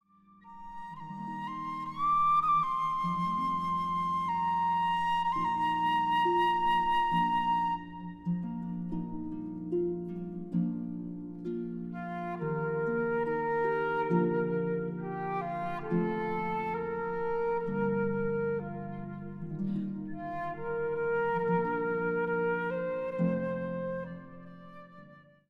Een prachtige Cd met fluit-harp muziek.
Op de Cd zijn hymns te horen uit verschillende landen.